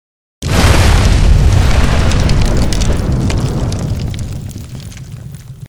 sk05_fire.wav